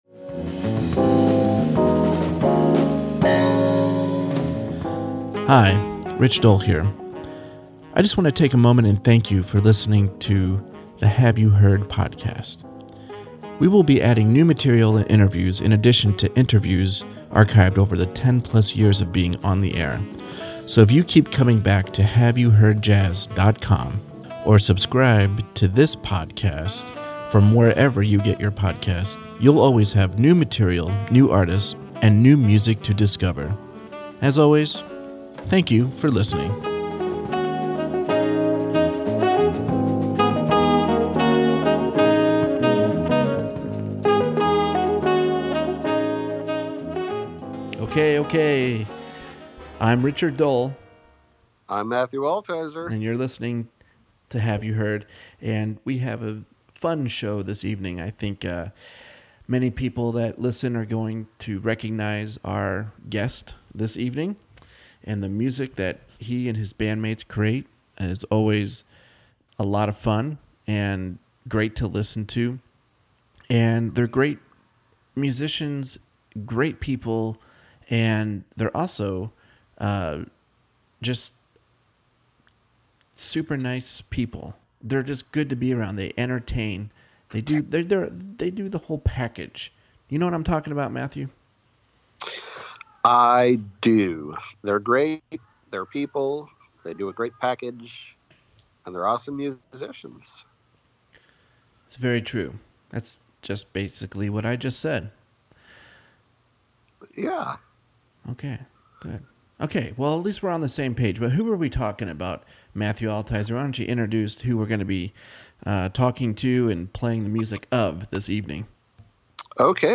joins us once again for this fun hour of discussion on and about his music, and music in general.